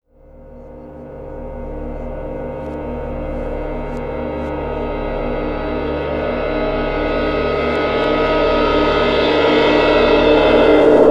Track 02 - Backward Cymbal OS 02.wav